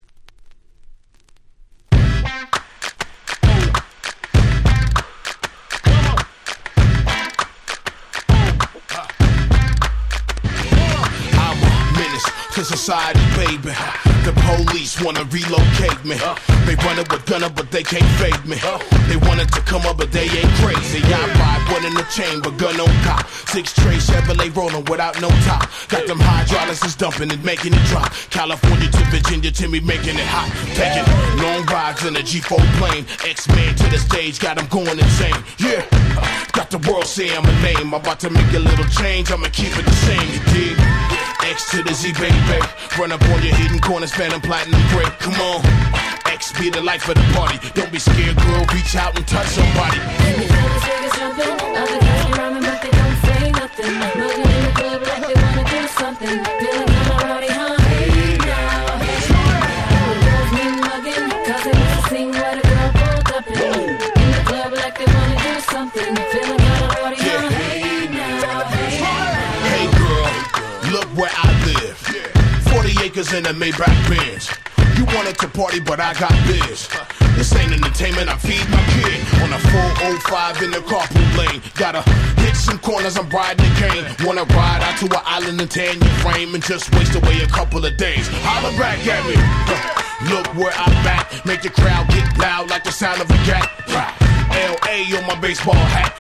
04' Smash Hit Hip Hop !!